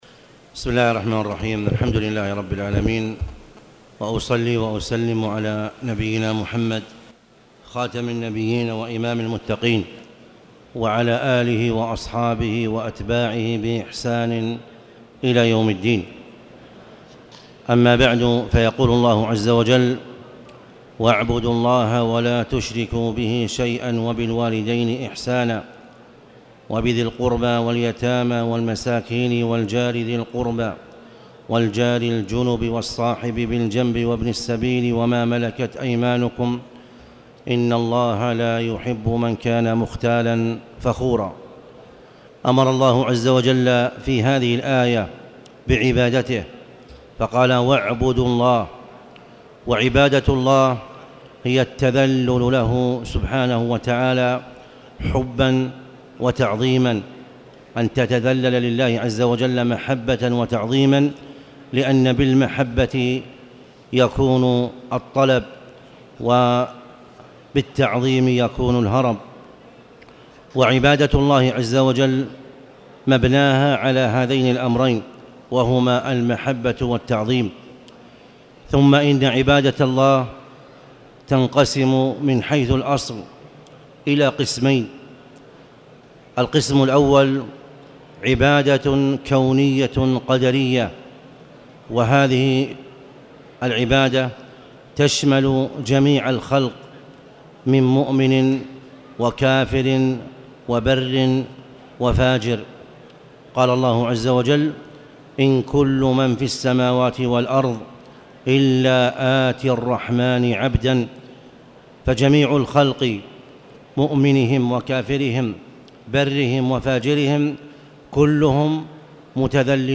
تاريخ النشر ٢٦ محرم ١٤٣٨ هـ المكان: المسجد الحرام الشيخ